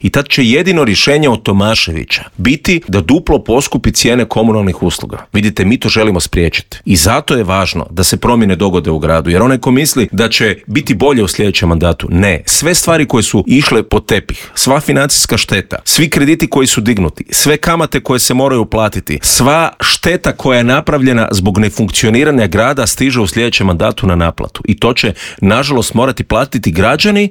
U studiju Media servisa ugostili smo nezavisnog kandidata za gradonačelnika Zagreba Davora Bernardića kojeg uvjerljivi trijumf Milanovića nije iznenadila jer su trendovi bili neupitni: